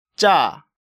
Category:Hangeul sounds